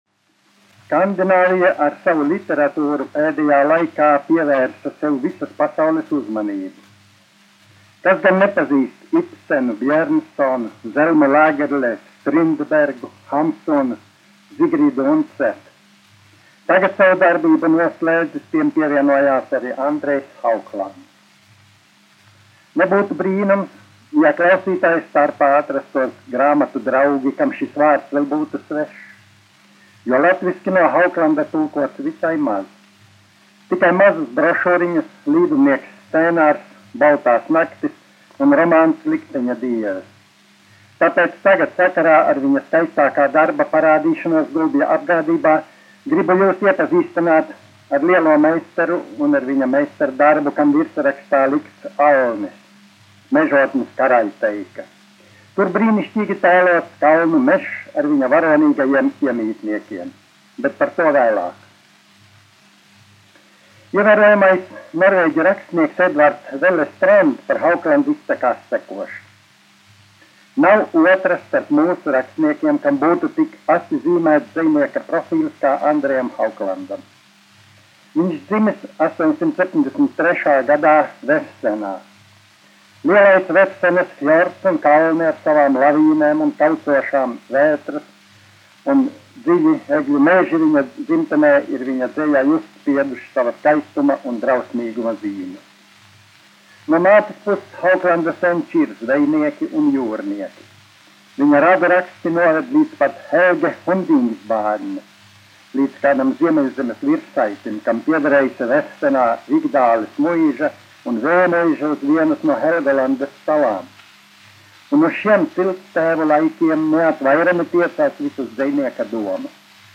1 skpl. : analogs, 78 apgr/min, mono ; 25 cm
Latviešu runas, uzrunas utt -- Vēsture un kritika
Skaņuplate